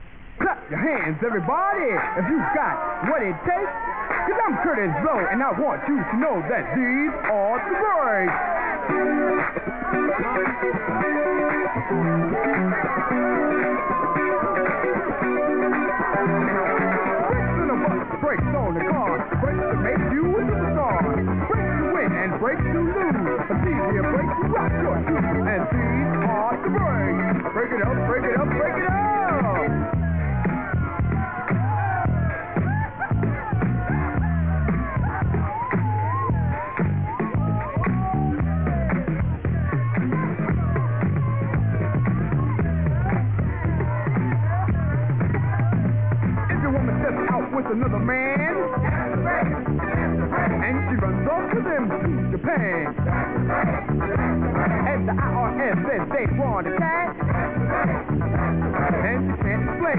70's/DISCO